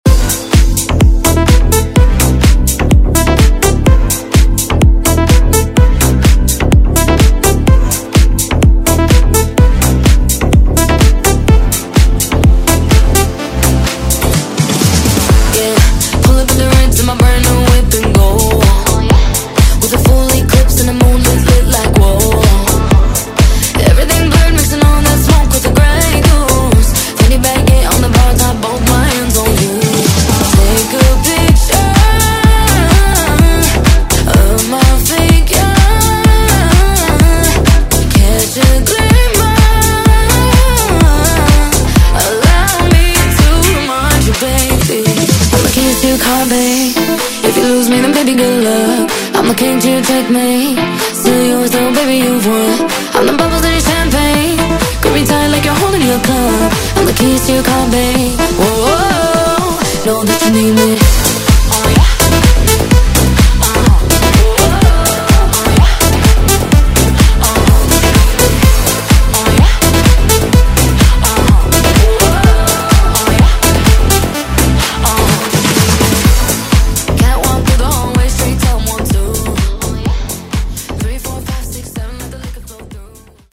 Genres: AFROBEAT , MOOMBAHTON , RE-DRUM
Clean BPM: 98 Time